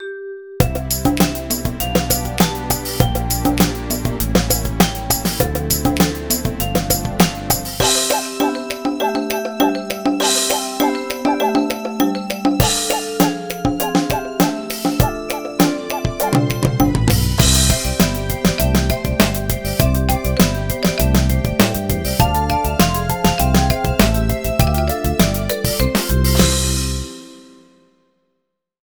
HR16 DRUMS.wav